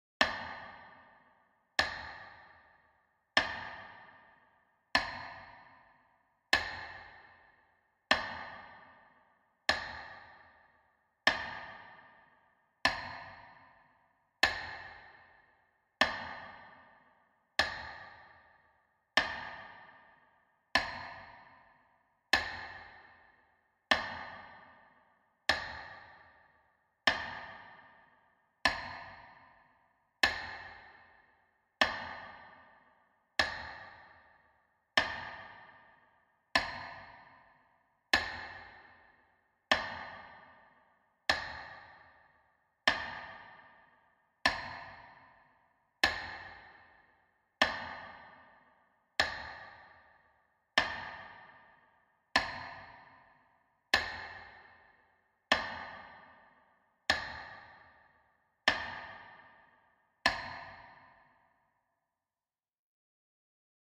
Звук метронома в минуту молчания